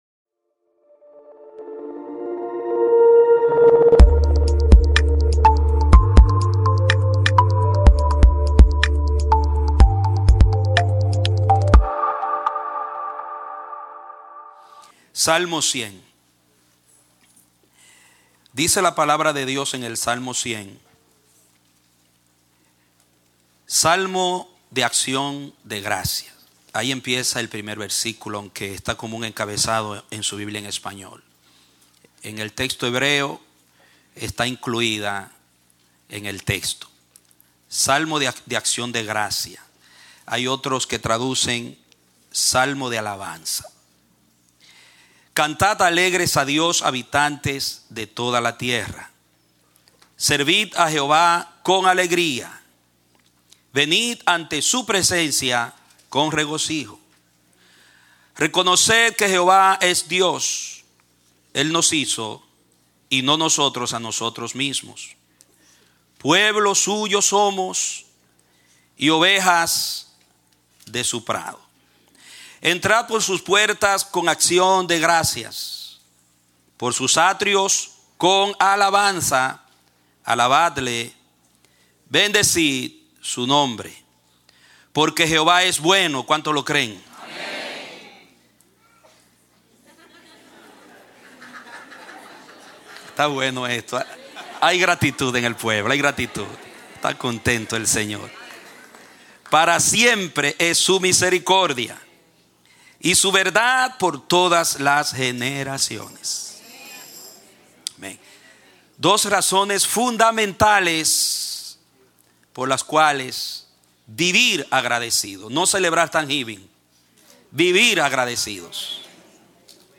Clase Bautismal – El Amanecer de la Esperanza Ministry